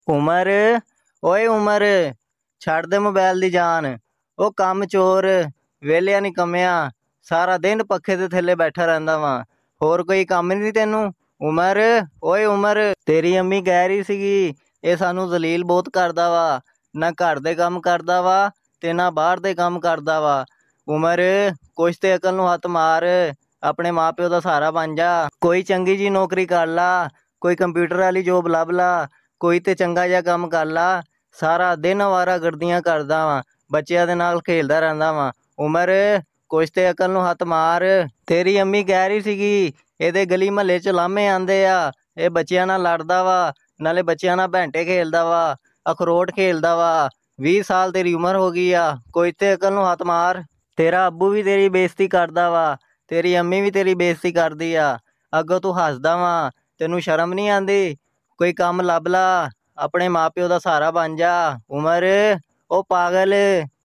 Goat